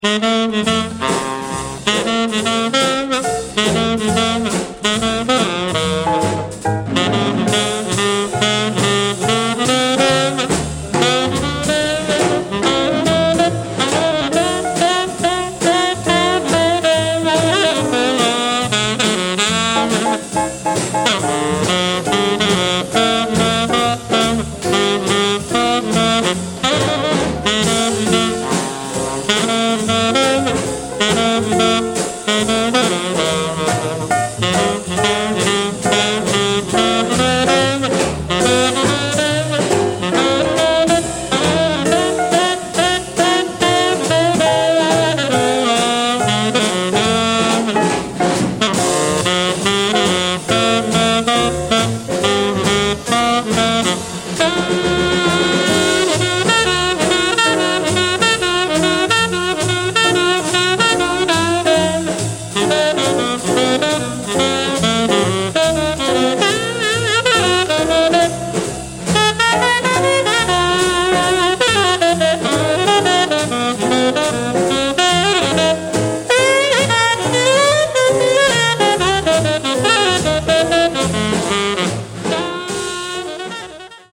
Rare mono repress from 1972.